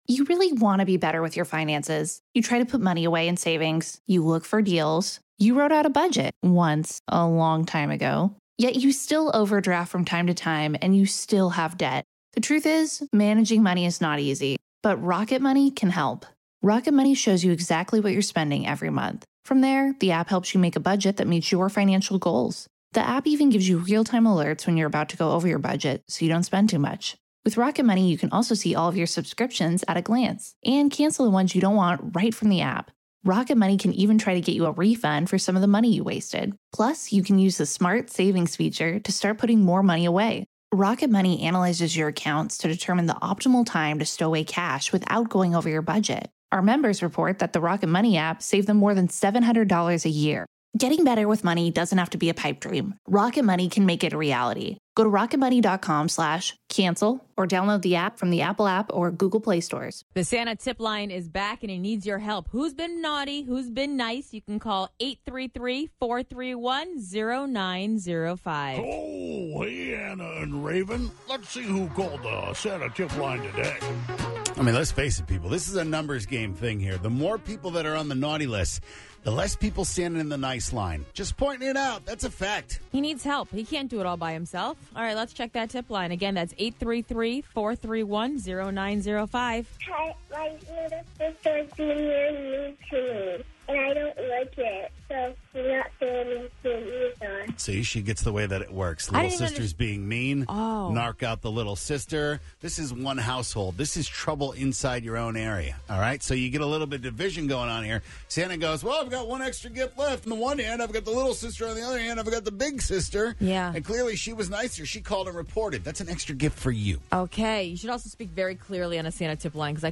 play the best voicemails in the podcast!